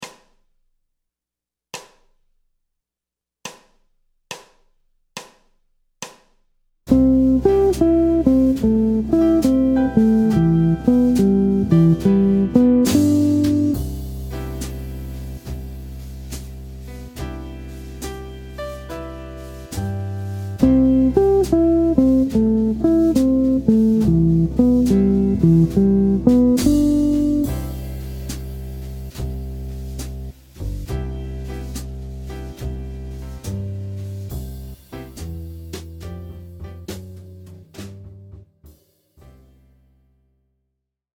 C Phrase construite à partir du motif de Triade 1 5 3 1